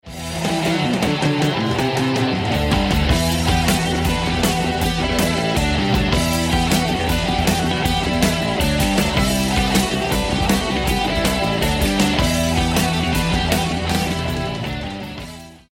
Рингтоны Без Слов
Рок Металл Рингтоны